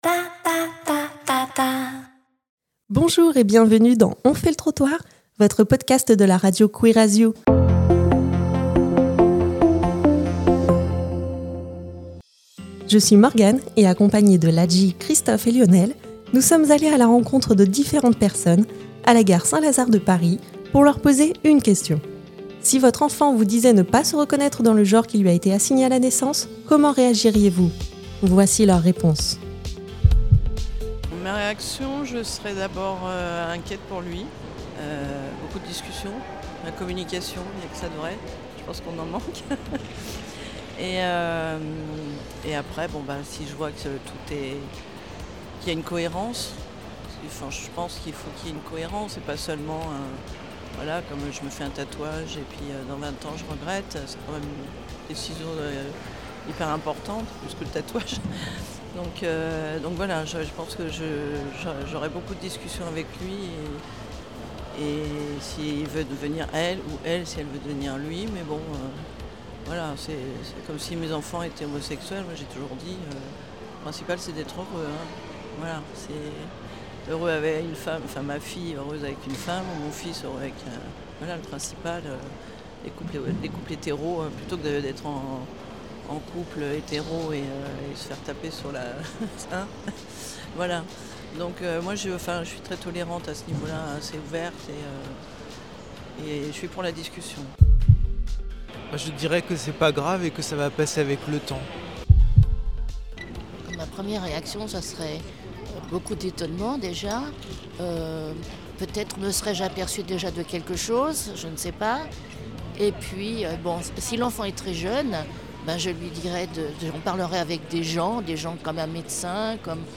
tendent leur micro à des passant·es de la gare Saint-Lazare à Paris
Les réponses sont diverses, spontanées, parfois touchantes, parfois maladroites, mais toujours sincères.
Dans ce podcast, c’est nous qui venons vers toi, dans la rue.